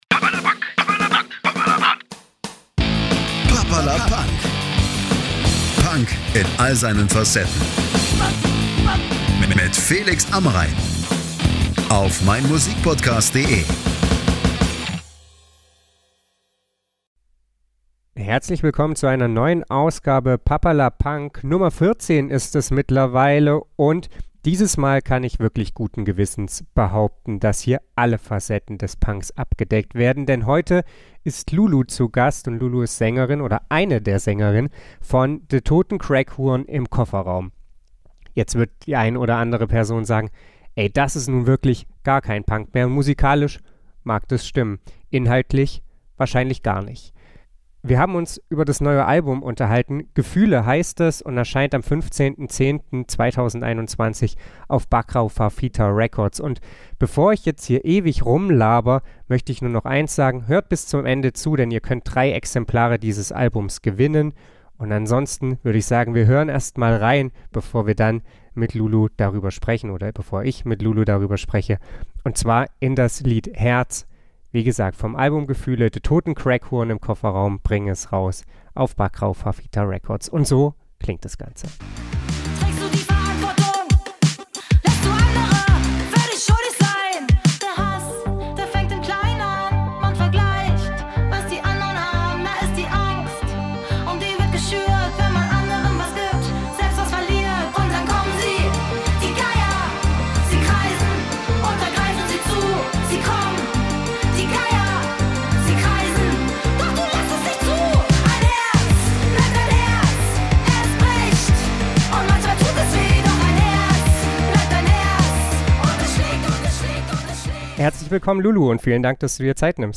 Den Podcast haben wir am 11.10.2021 via Skype aufgenommen.